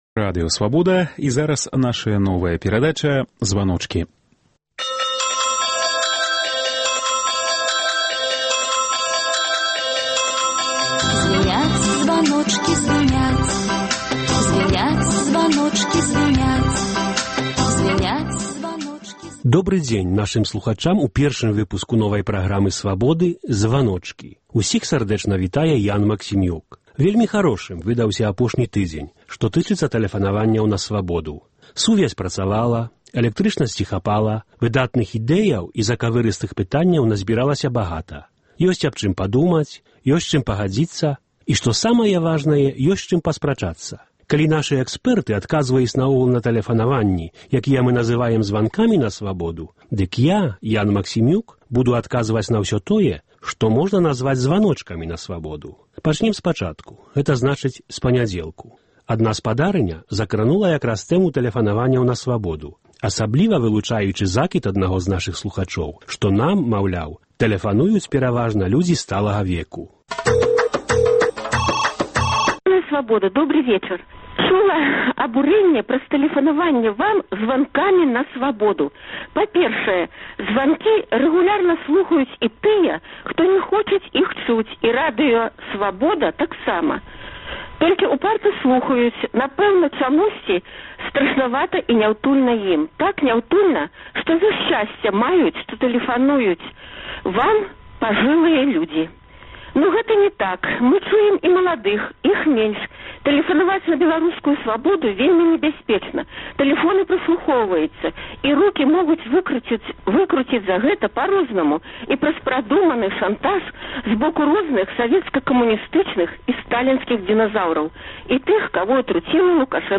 Вось праблемы, якія хвалююць нашых слухачоў у званках на "Свабоду".